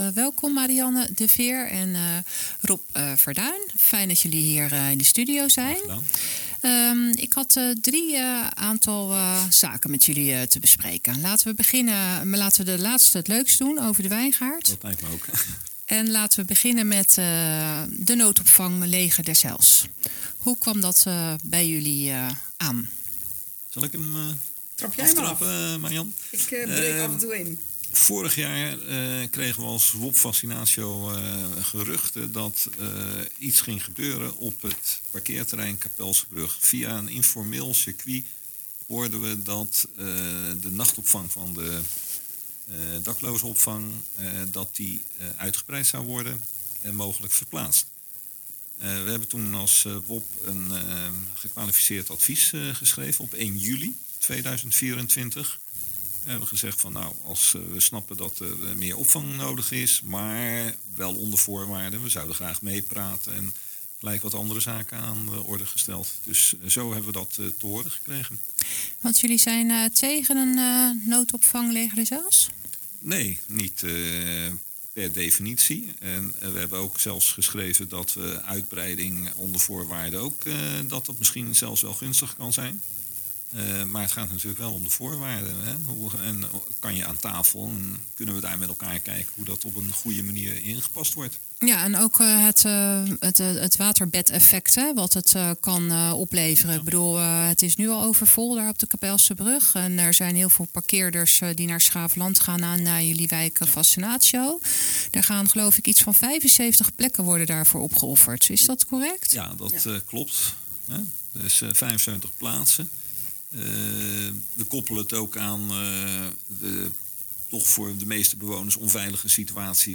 in gesprek met